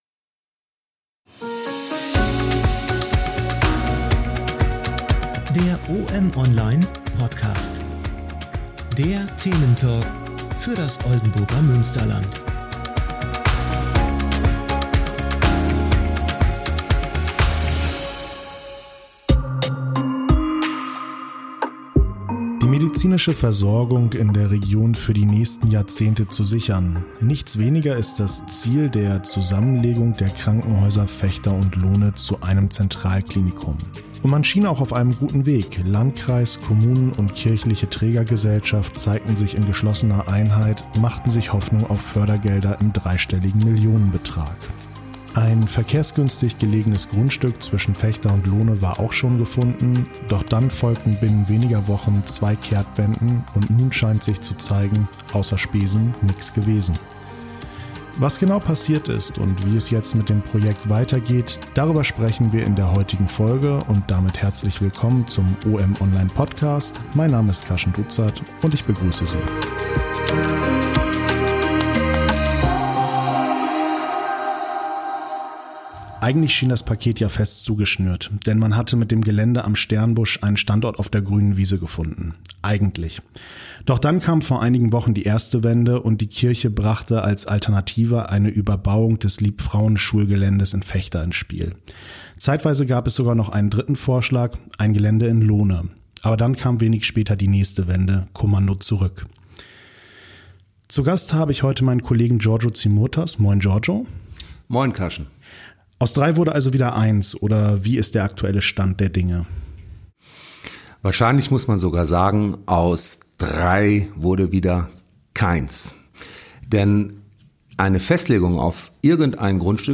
Der Thementalk im Oldenburger Münsterland.